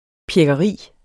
Udtale [ pjεgʌˈʁiˀ ]